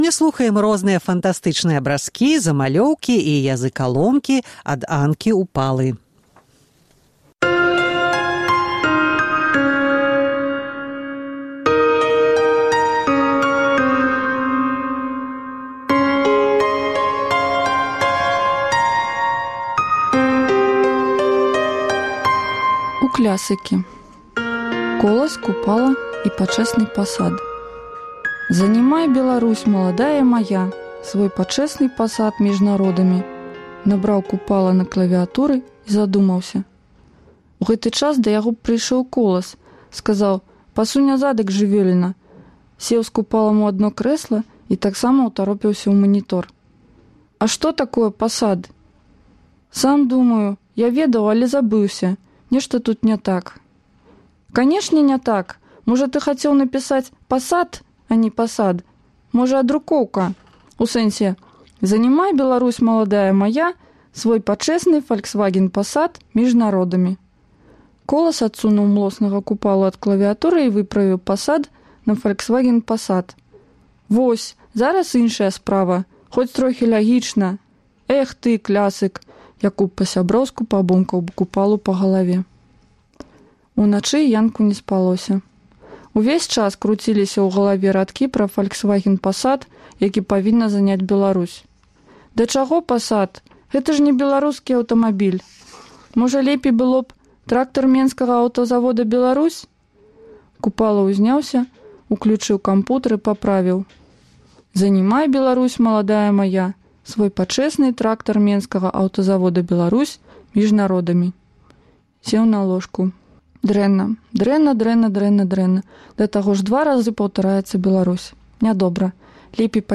Мы паўтараем перадачы з архіву Свабоды. У «Начной чытанцы» — 100 сучасных аўтараў чыталі свае творы на Свабодзе.